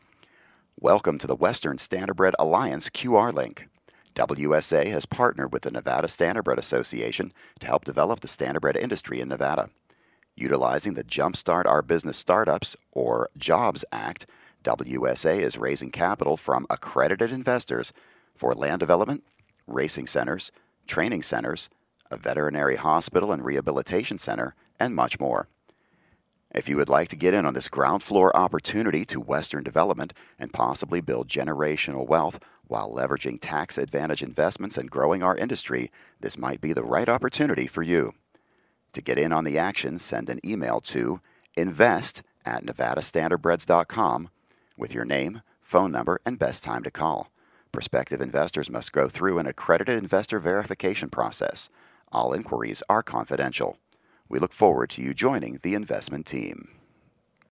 WSA Voiceover.mp3